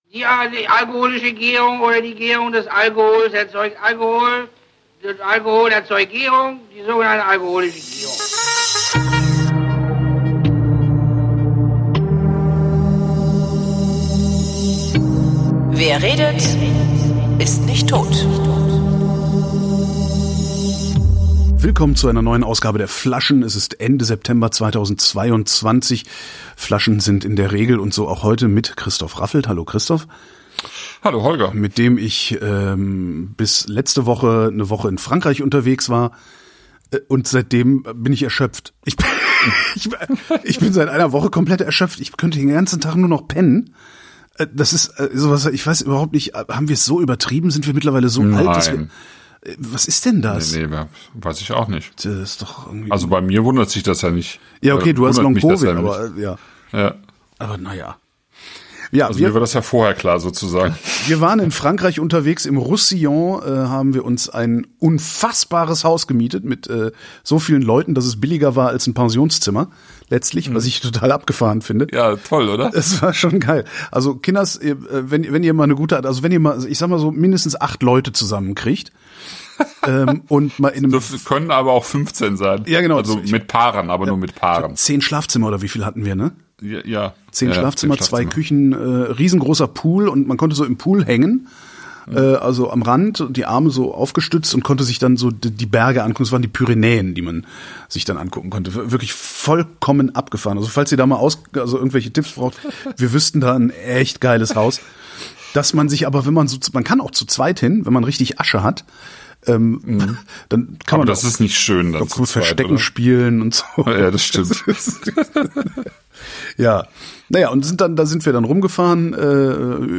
Wir trinken Matassa, Olla blanc 2021, Domaine de l’Horizon, Mar i Muntanya 2021 und Bruno Duchêne, La Luna 2020 und reden. Unter anderem über das Roussillon (nicht die Stadt).